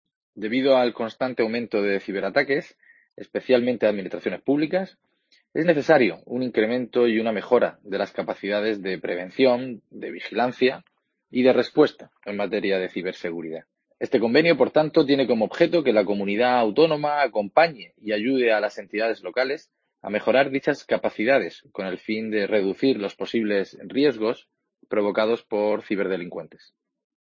Javier Martínez Gilabert, director general de Informática Corporativa